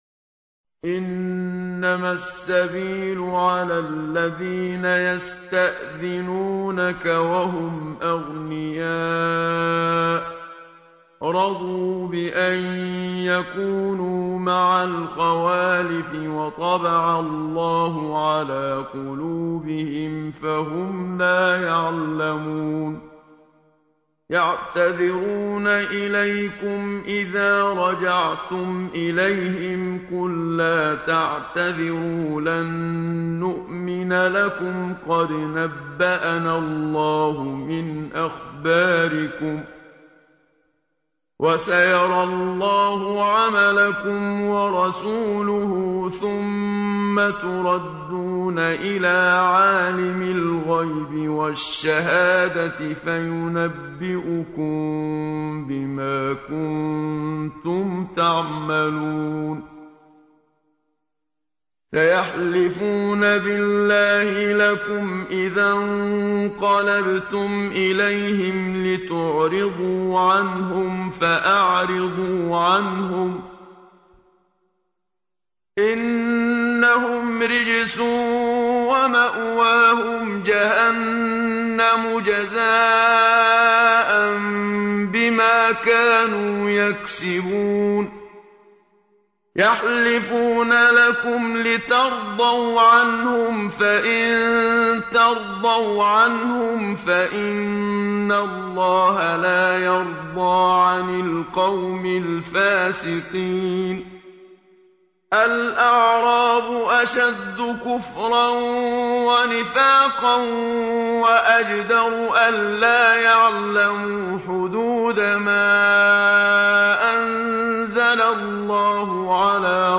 إذاعة طهران- الختمة المرتلة: تلاوة الجزء الحادي عشر من القرآن الكريم بصوت القارئ الشيخ محمد صديق المنشاوي.